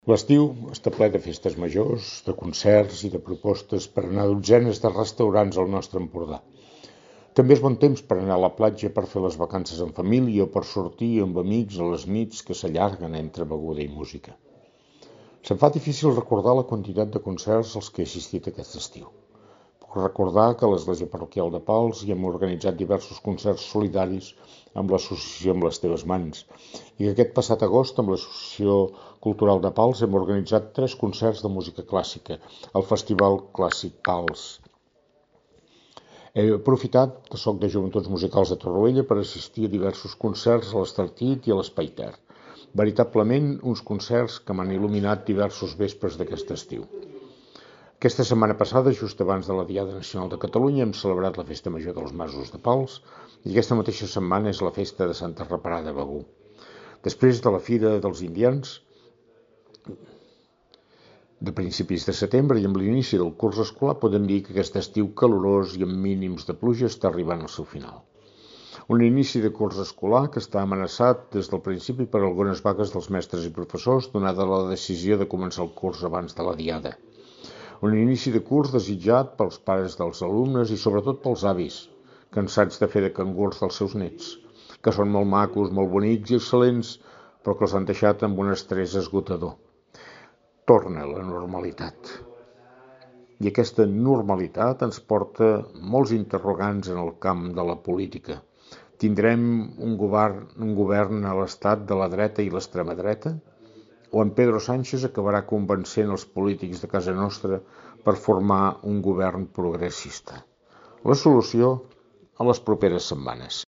Opinió